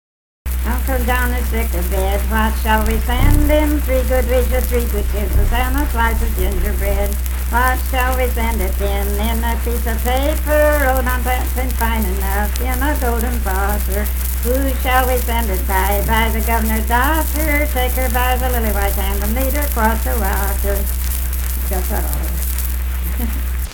Unaccompanied vocal music performance
Dance, Game, and Party Songs
Voice (sung)
Jackson County (W. Va.)